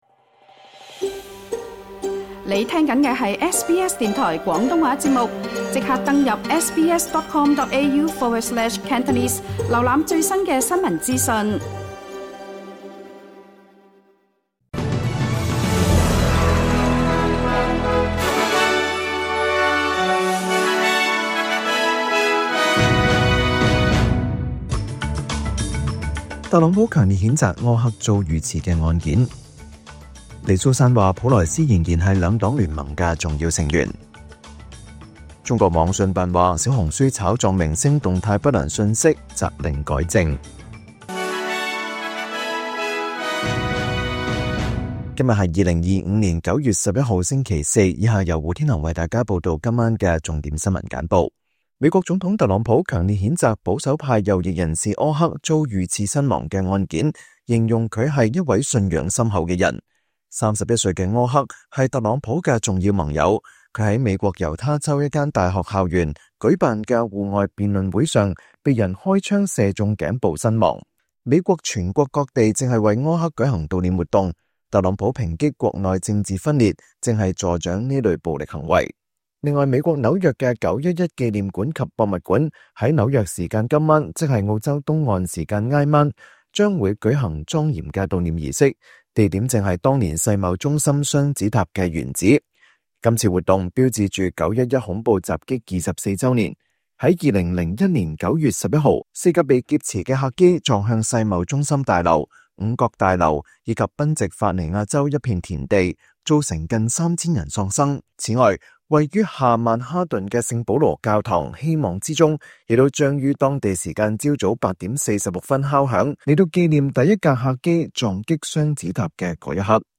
請收聽本台為大家準備的每日重點新聞簡報。
SBS 廣東話晚間新聞